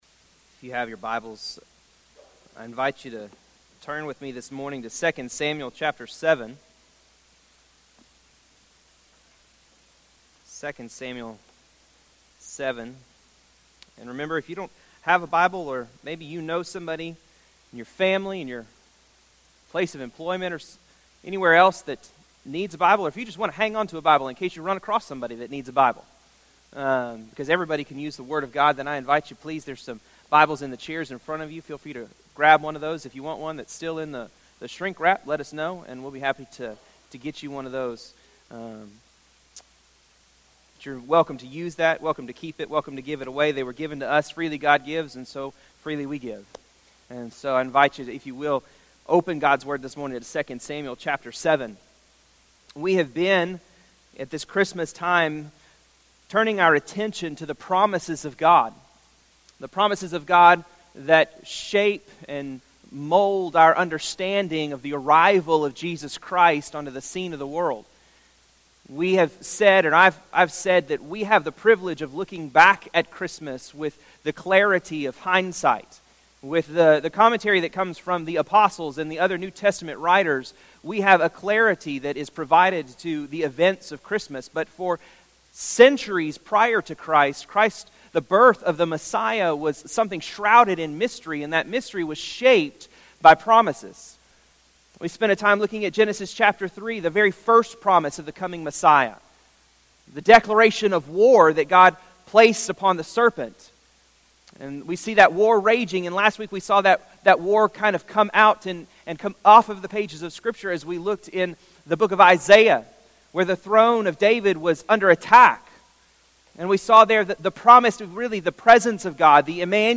sermon_12_9_18.mp3